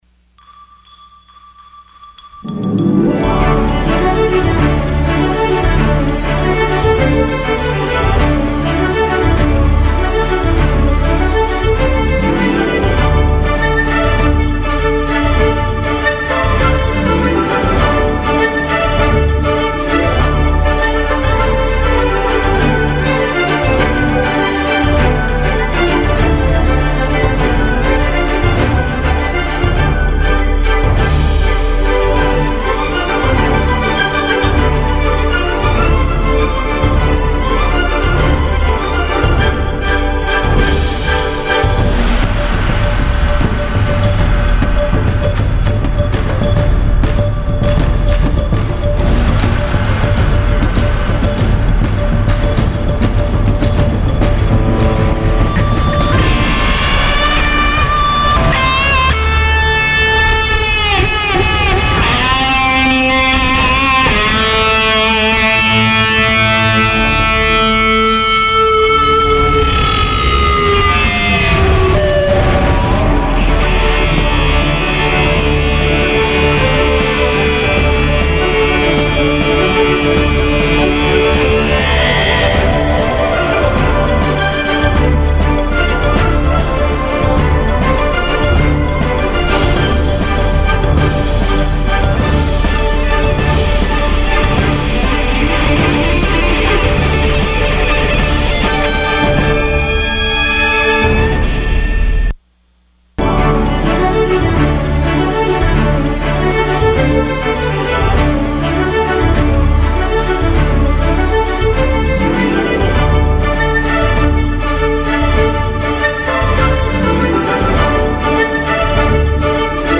Sessão Ordinária dia 19 de abril de 2016.
Gravação das Sessões